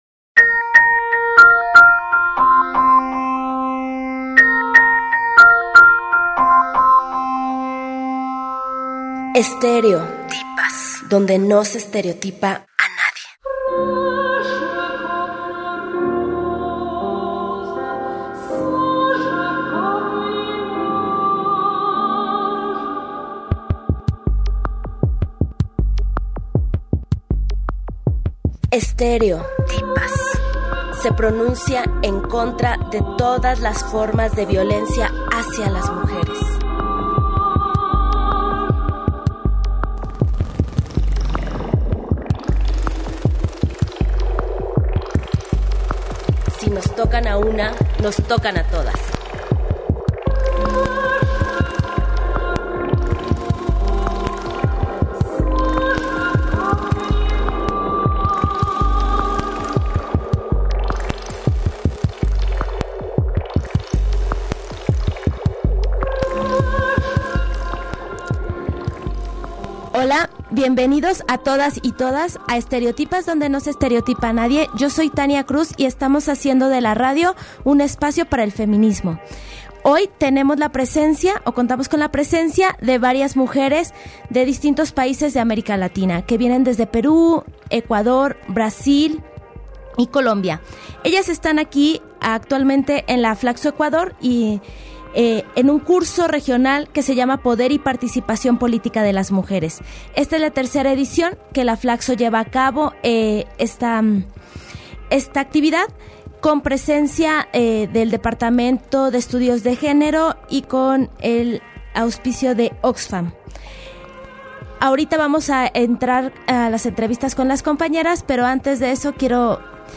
En el marco de la Escuela Regional de Formación de Mujeres Líderesas que tuvo lugar en FLACSO- ECUADOR a través del Departamento de Sociología y Estudios de Género, el pasado mes de Agosto compañeras de Brasil, Ecuador, Perú y Colombia estuvieron en Estéreo-tipas compartiendo sus experiencias. Hablamos sobre sus organizaciones y nos compartieron sus vivencias de haber estado en la Escuela de Formación Política.